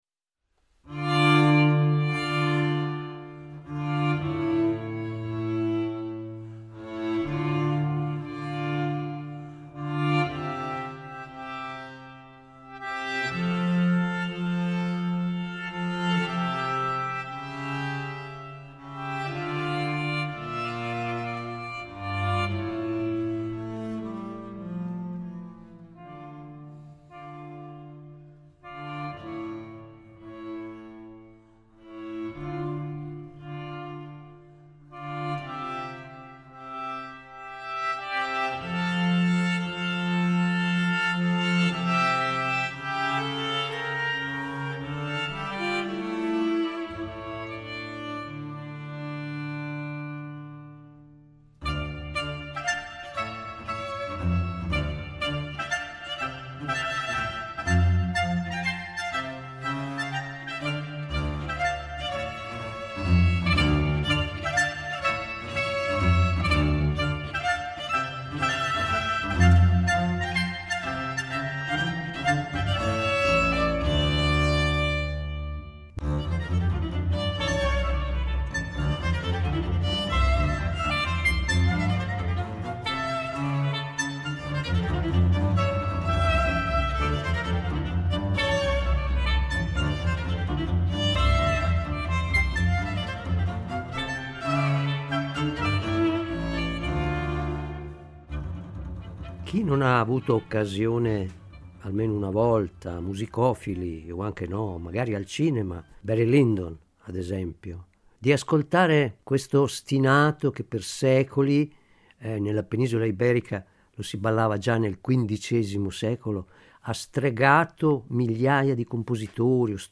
Ma c’è un particolare: lo sheng è uno strumento polifonico e può eseguire accordi fino a sei note. Due cose colpiscono soprattutto in questo album: la bontà dell’interpretazione e la naturalezza sorprendente con cui lo sheng si integra timbricamente e armonicamente in questo repertorio barocco. Non una traccia di esotismo, fortunatamente, ma una musicalità del tutto speciale e affascinante.